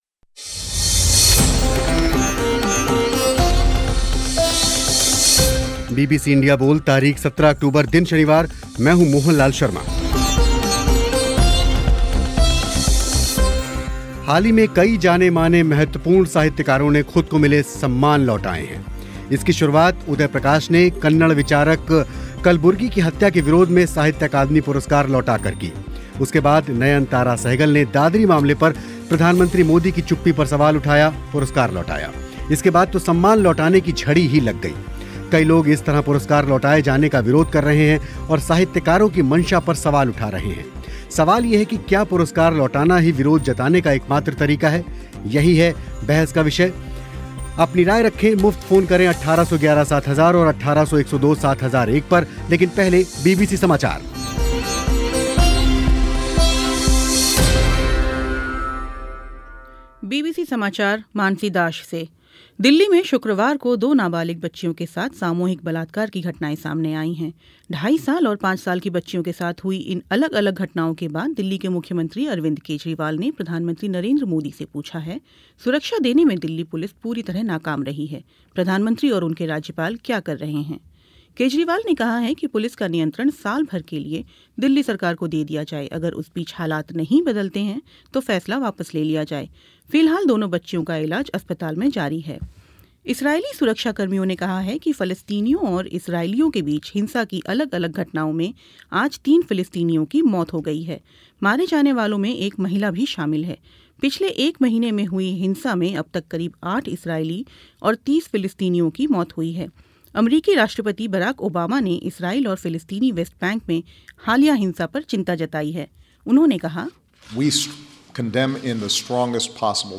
आज बीबीसी इंडिया बोल में यही था बहस का विषय कि क्या पुरस्कार वापस लौटाना सही है स्टूडियो में मौजूद थे वरिष्ठ पत्रकार ओम थानवी और फोन पर थे साहित्य अकादमी पुरस्कार लौटाने वाले मंगलेश डबराल और नंद भारद्वाज.